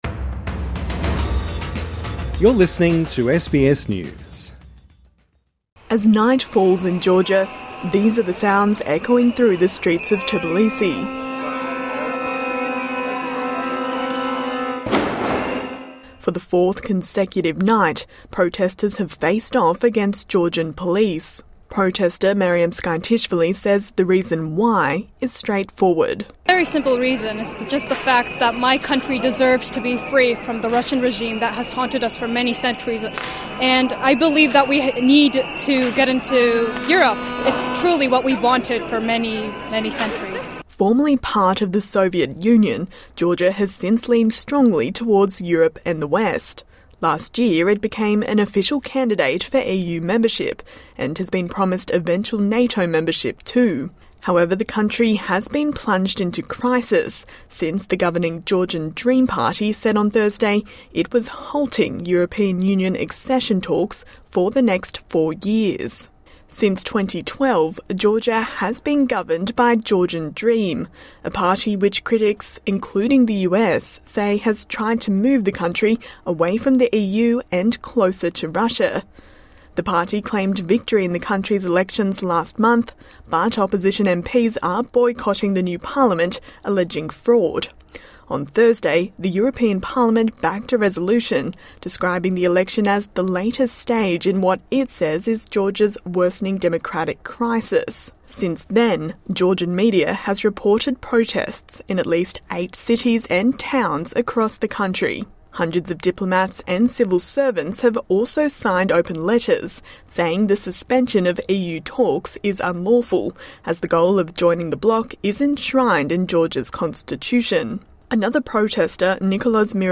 TRANSCRIPT As night falls in Georgia, these are the sounds echoing through the streets of Tbilisi [[tuh-buh-lee-see]].